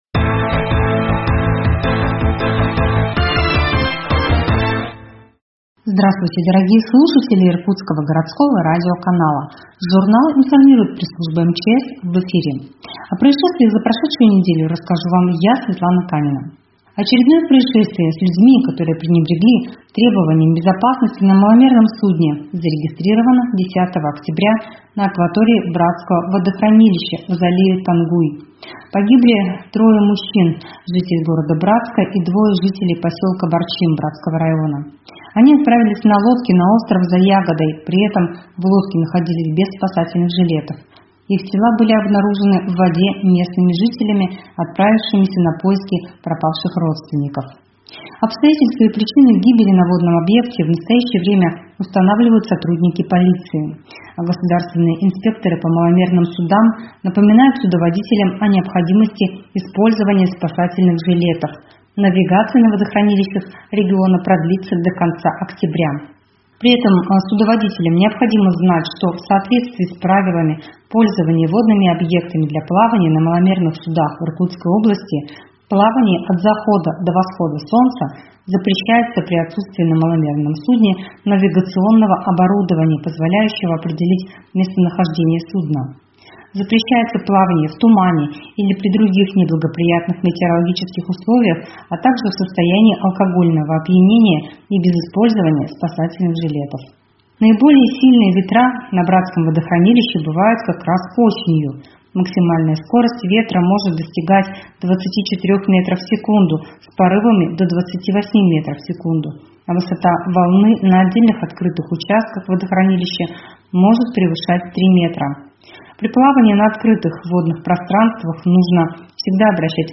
Радиожурнал «Информирует МЧС» 15.10.2021